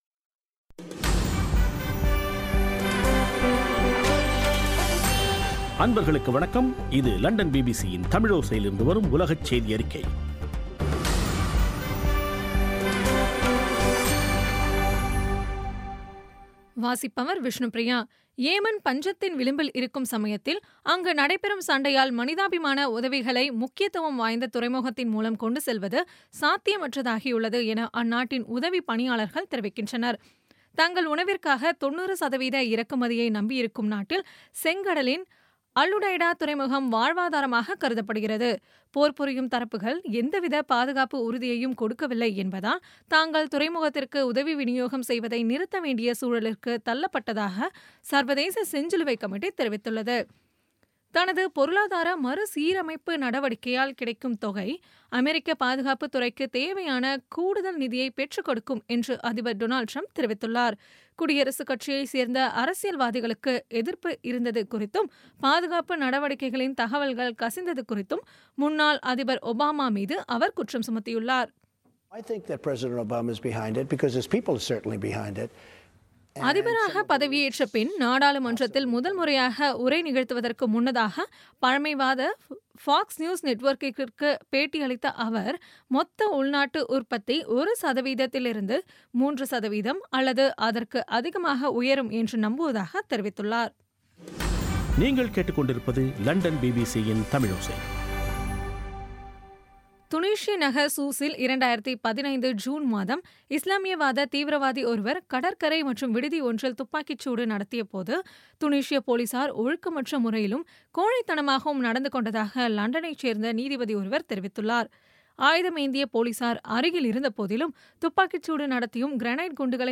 பிபிசி தமிழோசை செய்தியறிக்கை (28/02/17)